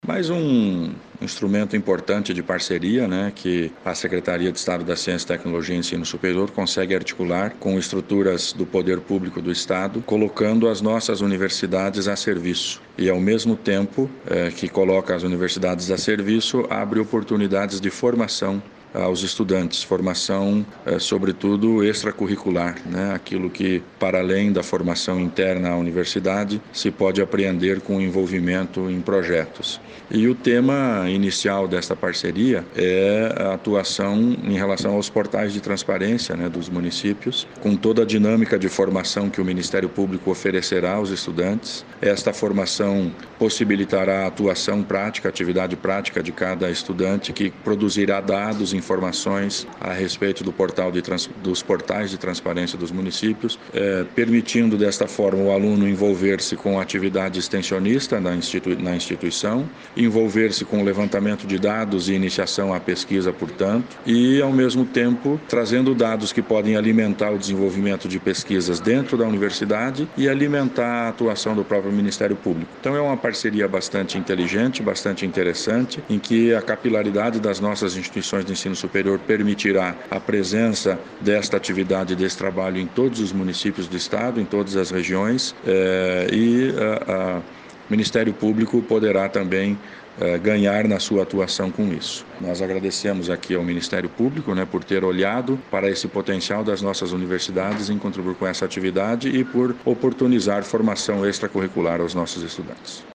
Sonora do secretário estadual da Ciência, Tecnologia e Ensino Superior, Aldo Nelson Bona, sobre capacitação de estudantes de graduação em parceria com o MPPR